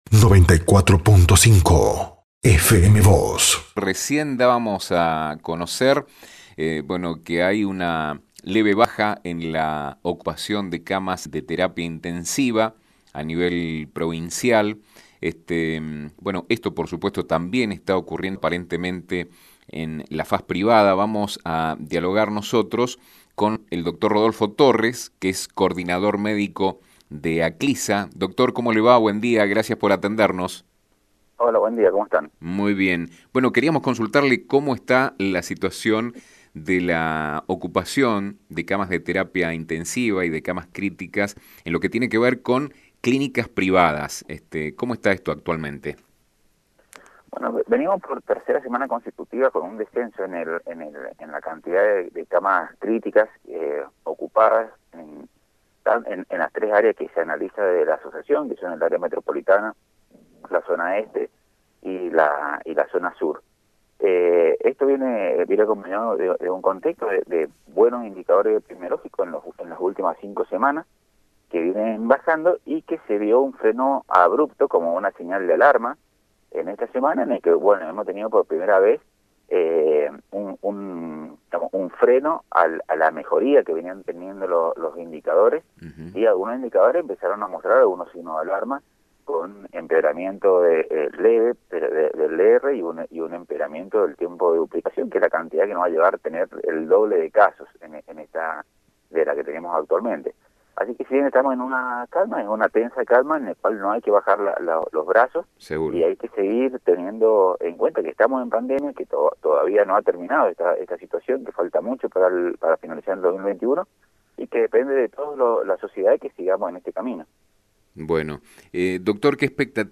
Sobre este tema habló con FM Vos (94.5)